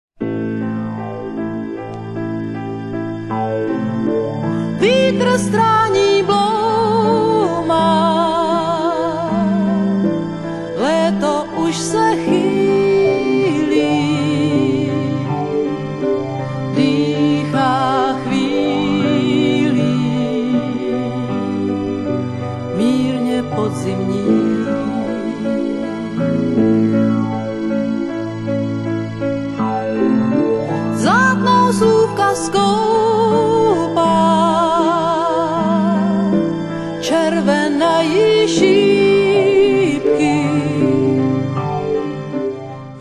zpěvačka řeckého původu.
jazzrockově laděném